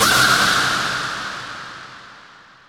Index of /90_sSampleCDs/Houseworx/12 Vocals